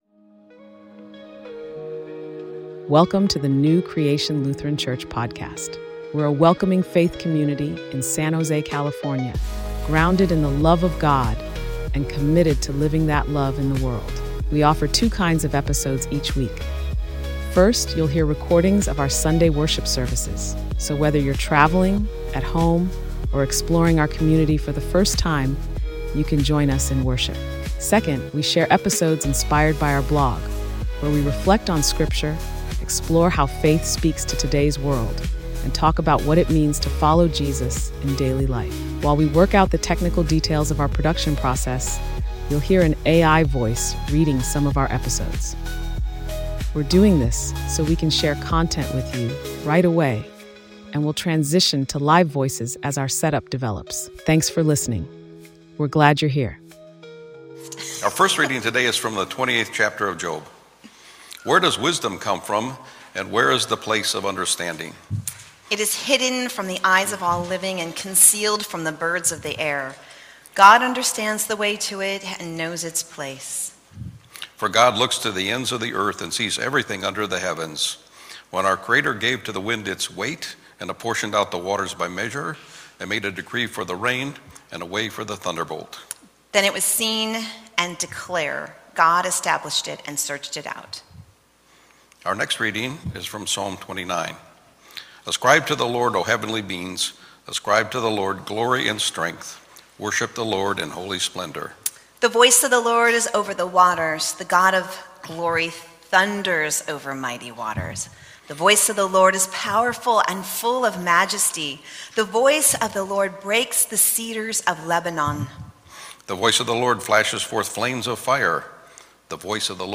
Sermon: Exploring Creation: Christ, Cosmos, and Our Place in It